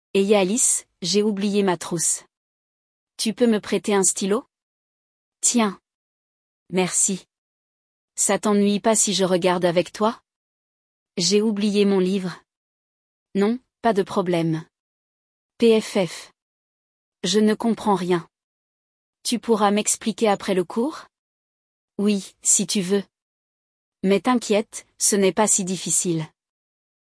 Hoje, vamos acompanhar o diálogo entre dois universitários, onde um pede um favor ao outro.
LE DIALOGUE